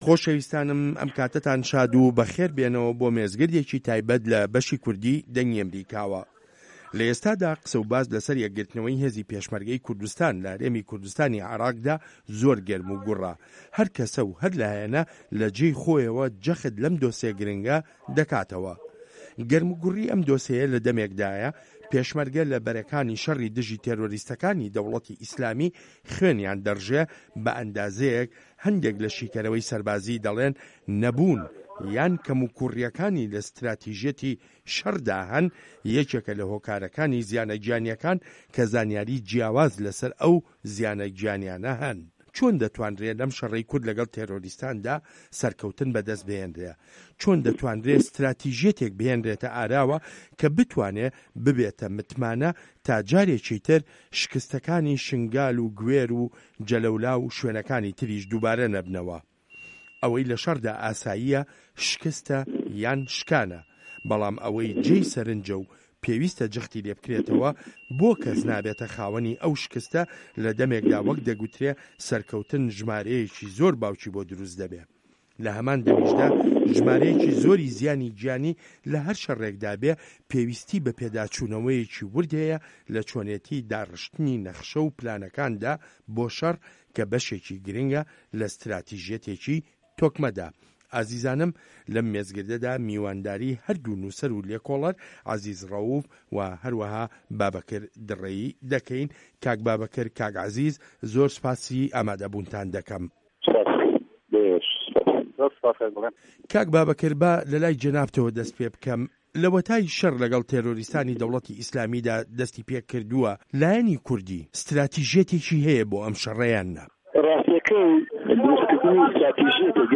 مێزگرد: کورد و ستراتیژیه‌تی شه‌ڕی دژی ڕێکخراوی ده‌وڵه‌تی ئیسلامی